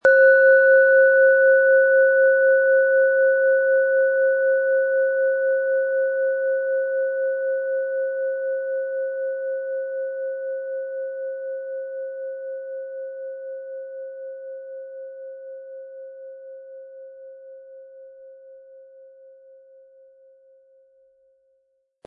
Diese Planeten-Klangschale DNA wurde in überlieferter Weise von Hand gearbeitet.
Im Sound-Player - Jetzt reinhören können Sie den Original-Ton genau dieser Schale anhören.
Im Preis enthalten ist ein passender Klöppel zur Klangschale, der die Schwingung der Schale gut zur Geltung bringt.
MaterialBronze